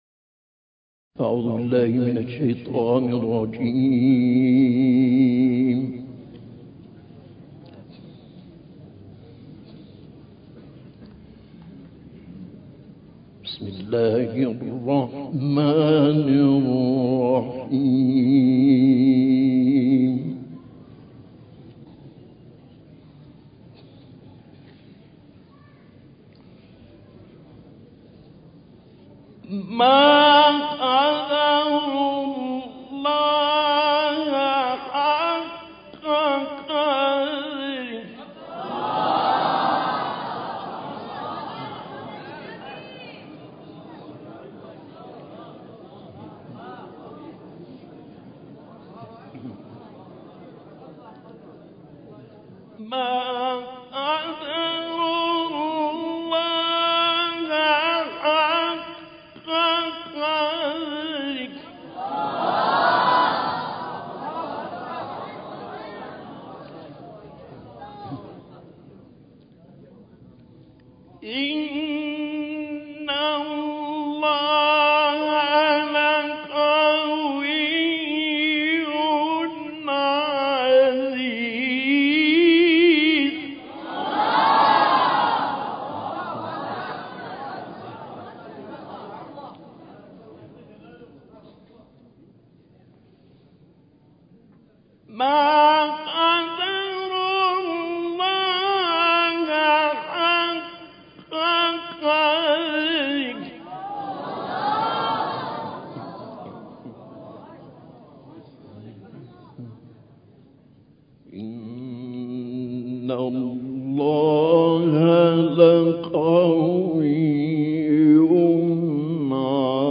تلاوت سوره حج با صدای ملکوتی استاد شعیشع
تهران - الکوثر : تلاوت نورانی و ماندگار سوره حج از ایه 74 تا آخر سوره با صدای استاد شعیشع که در سال 1377 در تهران اجرا شده است.